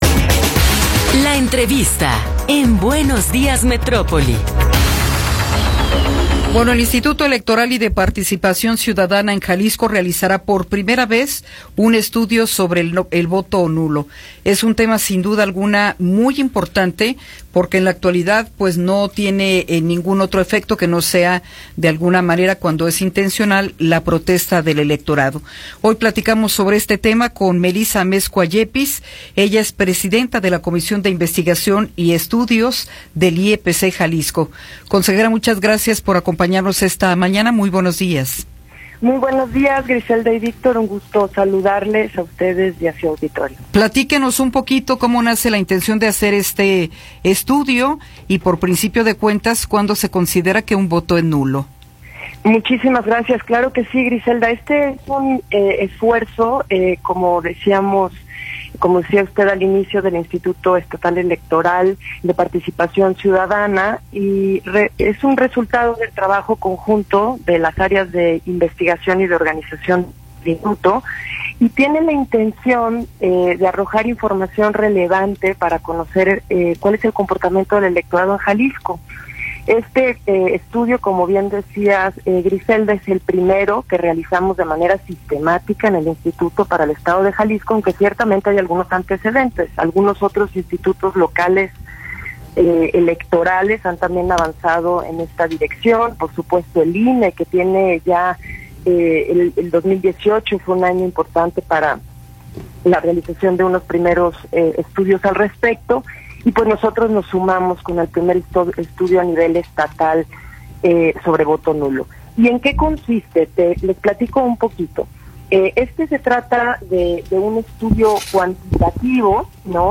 Entrevista con Melissa Amezcua Yépiz